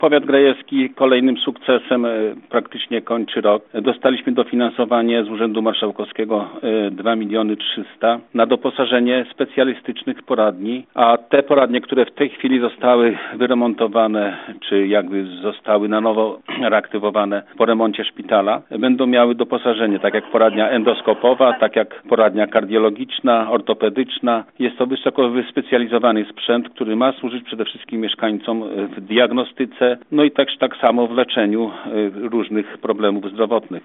Mówił Radiu 5 Waldemar Remfeld, starosta grajewski.